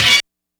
HORN BLAST-R.wav